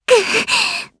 Aisha-Vox_Damage_jp_01.wav